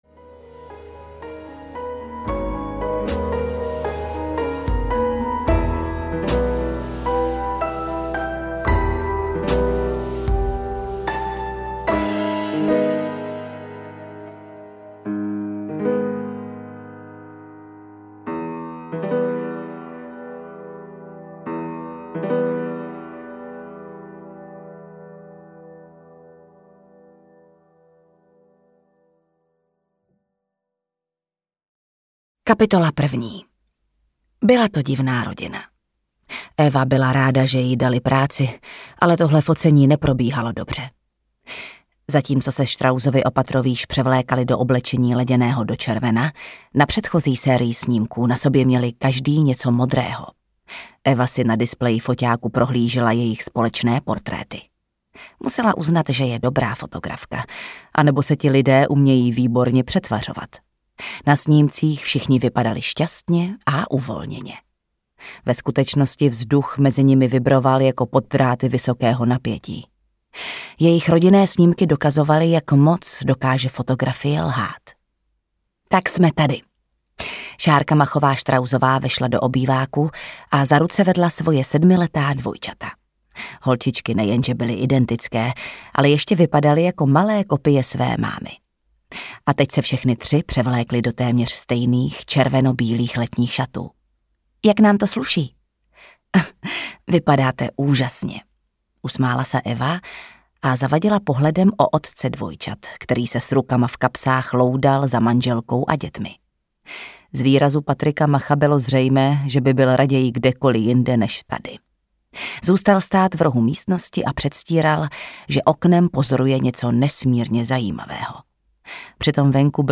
Vraní oko audiokniha
Ukázka z knihy
• InterpretJana Stryková